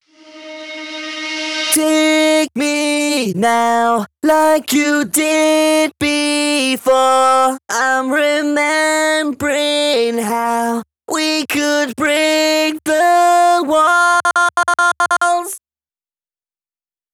Vocal-Hook Kits